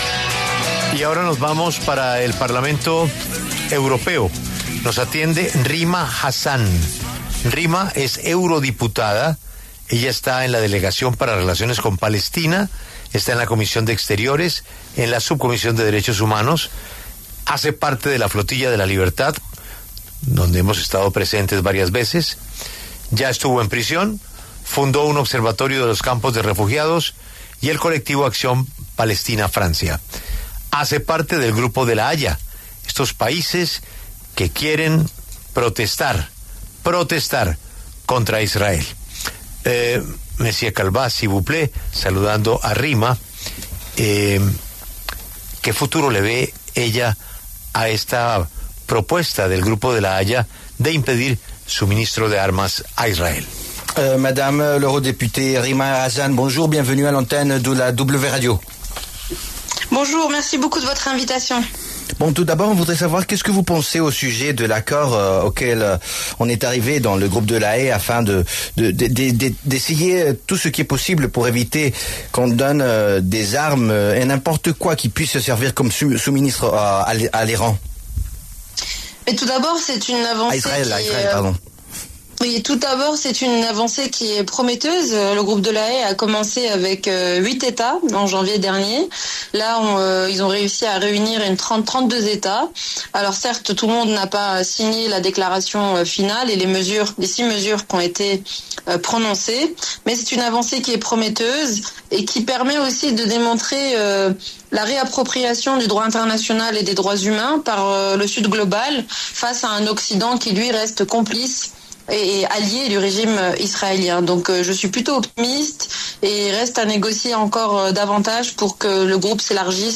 La eurodiputada franco-palestina Rima Hassan habló en La W sobre el compromiso adquirido por los países del Grupo de La Haya de impedir el suministro de armas a Israel.